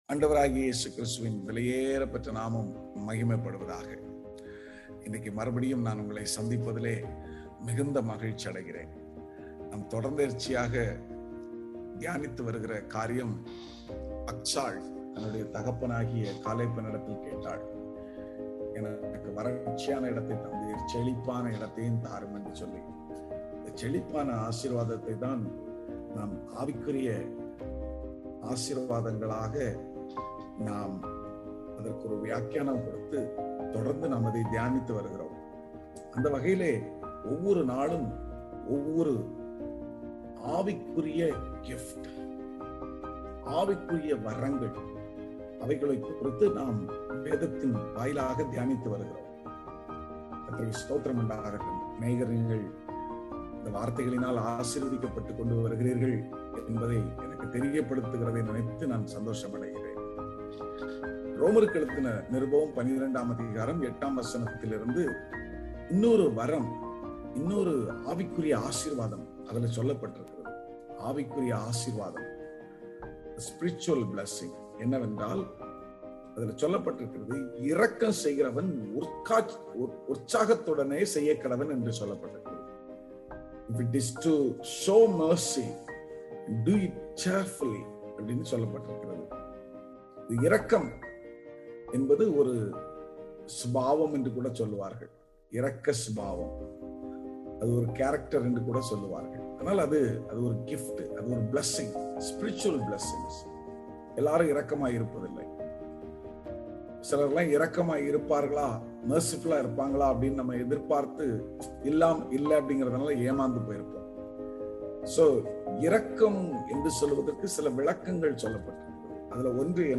Morning Devotion